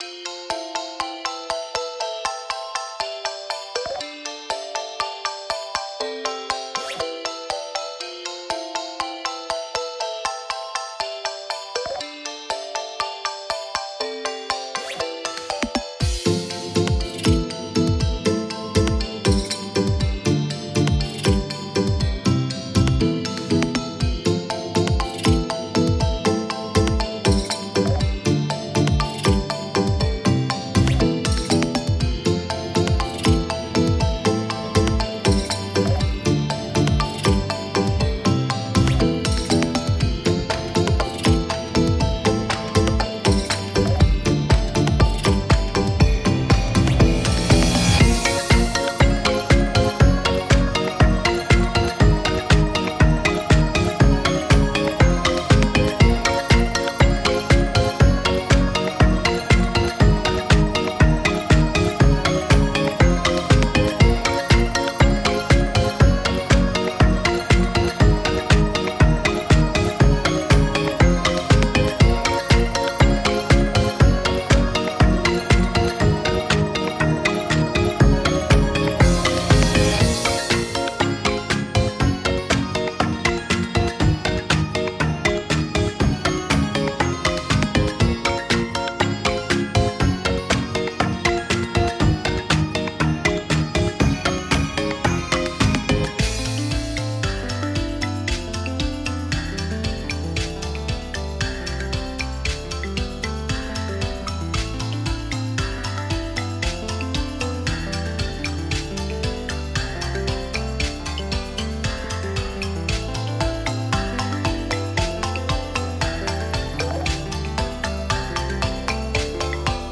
off vocal音源↓
↑音だけ